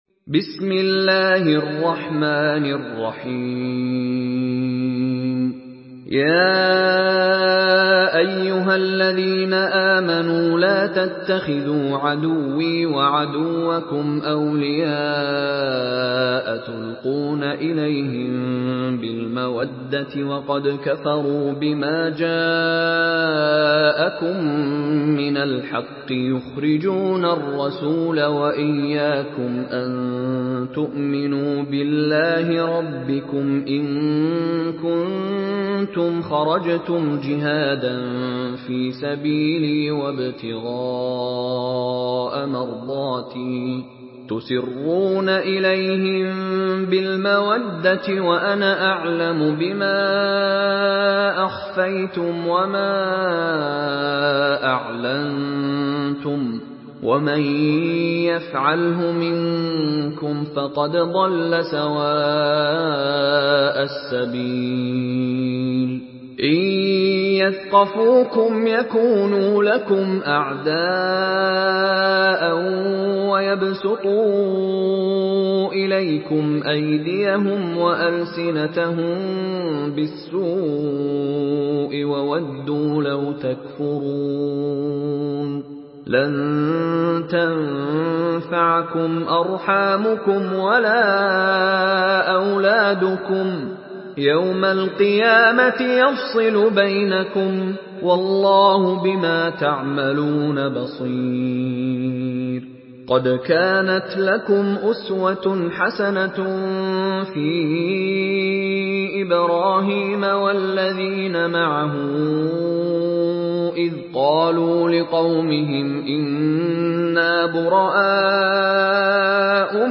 Surah Al-Mumtahinah MP3 in the Voice of Mishary Rashid Alafasy in Hafs Narration
Murattal Hafs An Asim